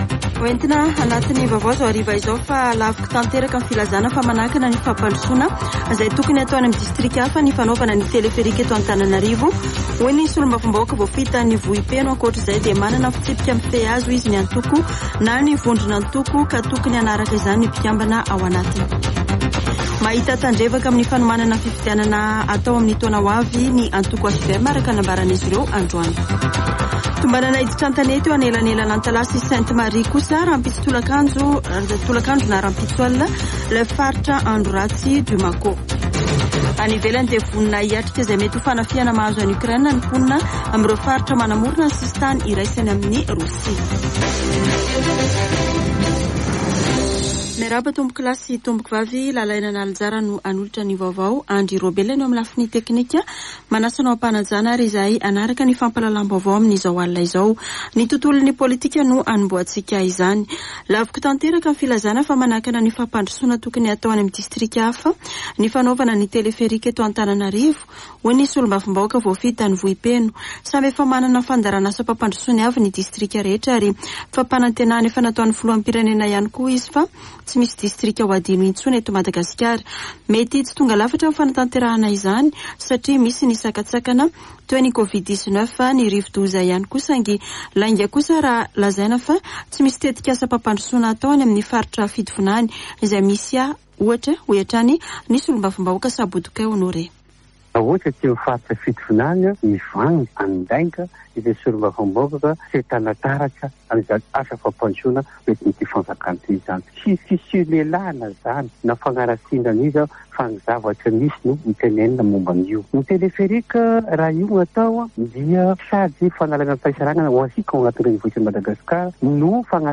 [Vaovao hariva] Alatsinainy 14 febroary 2022